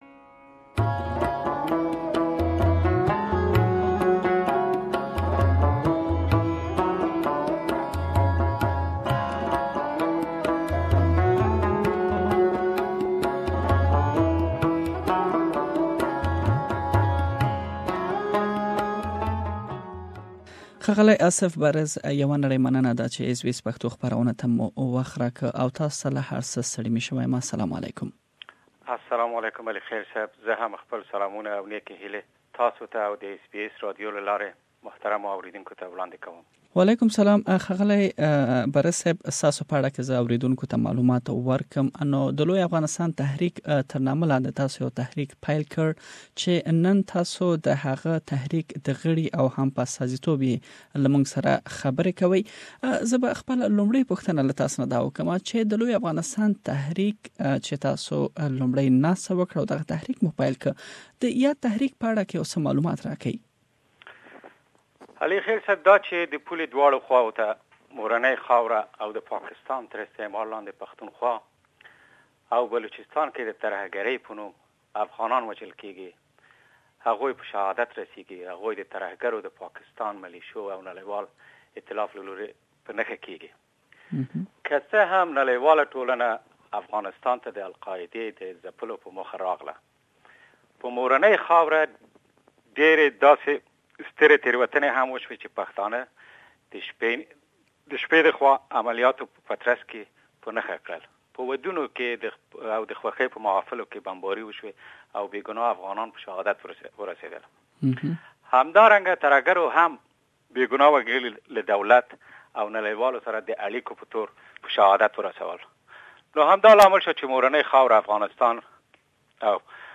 An Interview with the memeber of the Great Afghanistan Movement